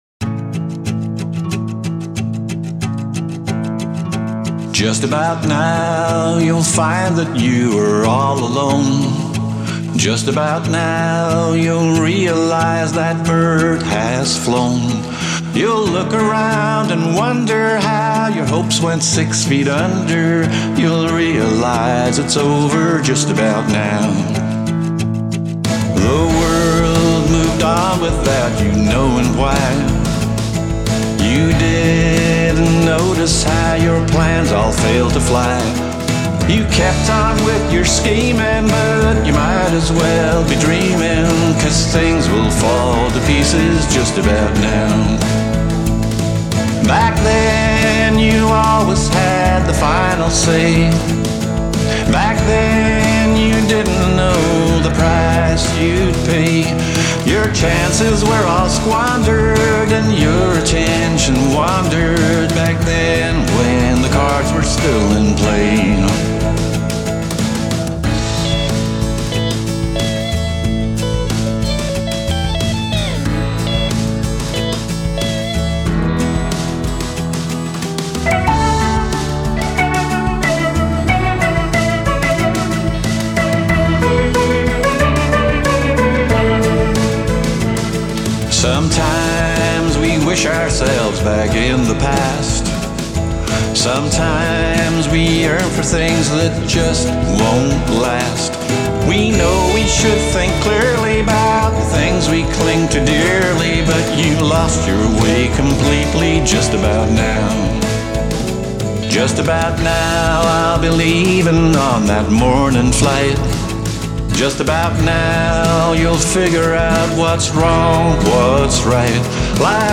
Vocal